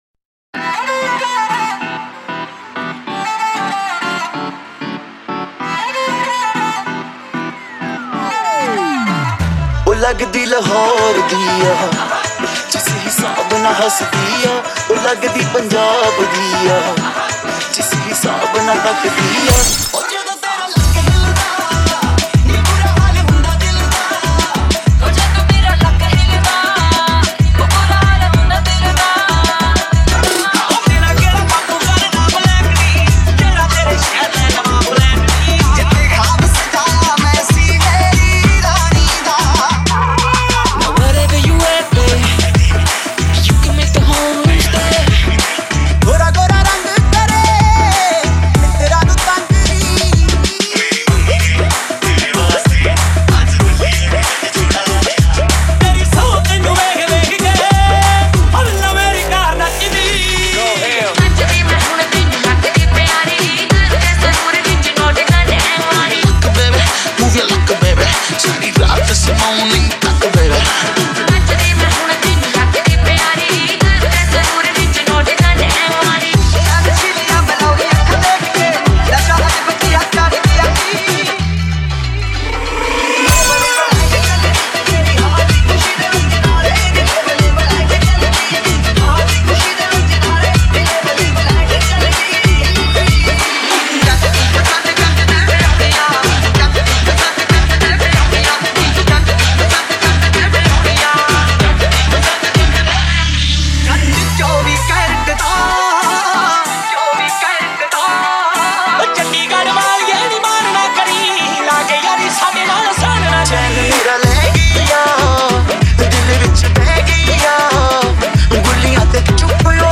DJ Remix Mp3 Songs > Latest Single Dj Mixes